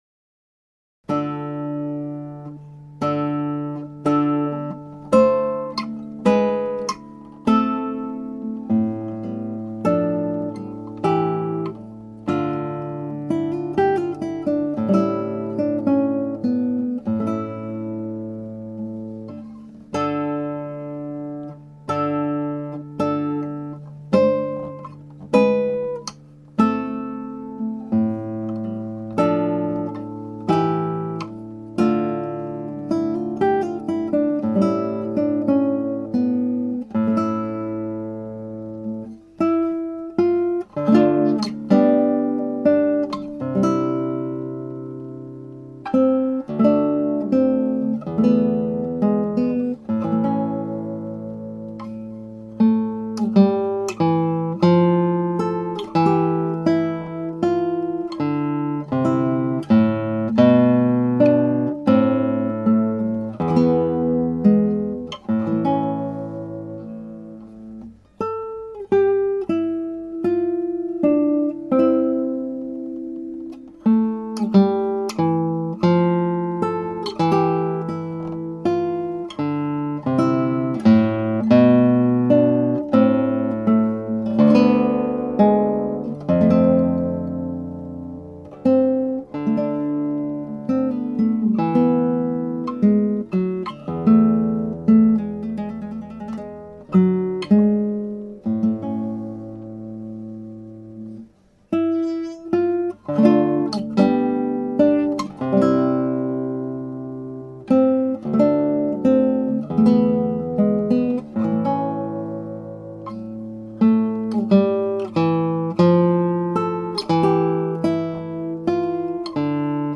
(アマチュアのクラシックギター演奏です [Guitar amatuer play] )
6小節2拍目の6連符が少し長めになってしまっています。
特に低音の下旋律を大事に弾いてみました。
aria2_6pieces_lute.mp3